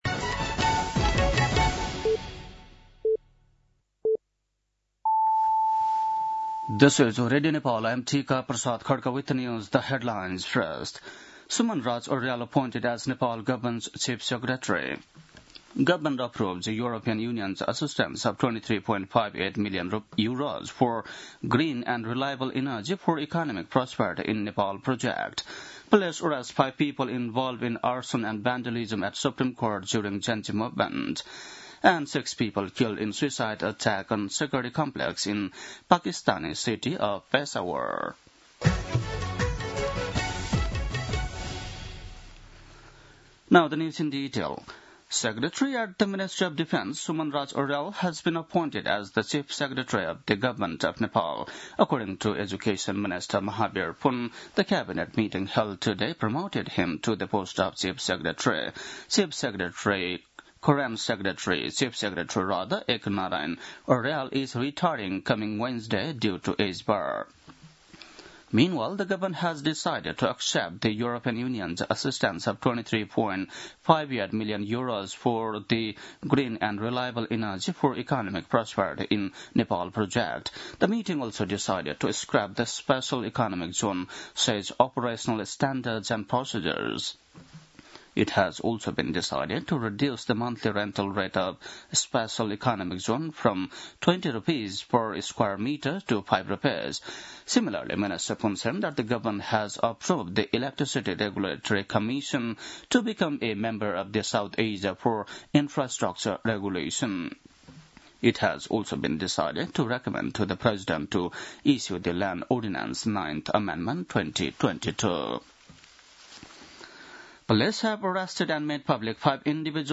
बेलुकी ८ बजेको अङ्ग्रेजी समाचार : ८ मंसिर , २०८२
8-pm-english-news-8-8.mp3